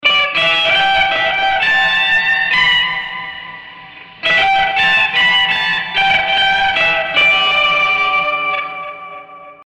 描述：Jazzy
标签： 100 bpm Blues Loops Guitar Electric Loops 1.62 MB wav Key : Unknown
声道立体声